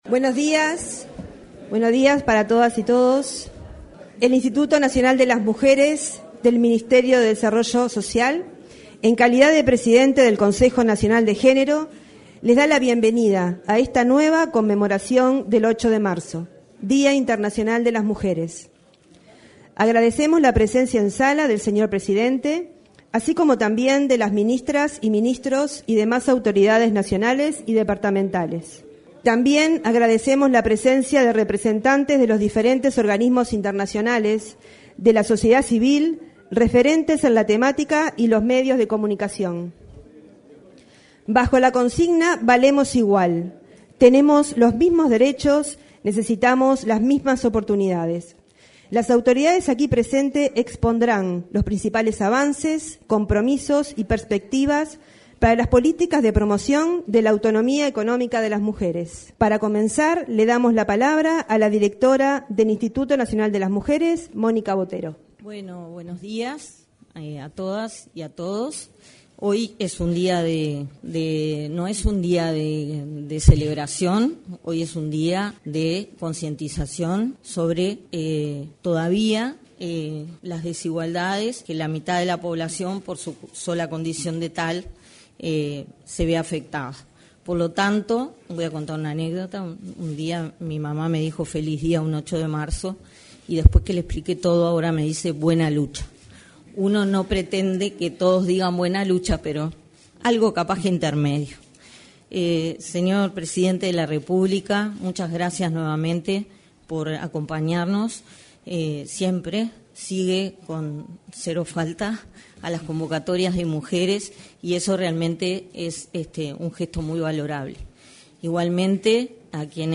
Acto de rendición de cuentas y balance de implementación de las políticas públicas de igualdad de género 08/03/2023 Compartir Facebook X Copiar enlace WhatsApp LinkedIn En la actividad, que se desarrolló en el salón de actos de la Torre Ejecutiva y a la que concurrió el presidente de la República, Luis Lacalle Pou, se expresaron la directora nacional de Inmujeres, Monica Bottero; la directora de Cuidados del Mides, Florencia Krall; el presidente del Banco Central del Uruguay, Diego Labat; el ministro interino de Turismo, Remo Monzeglio, y el titular de Trabajo y Seguridad Social, Pablo Mieres.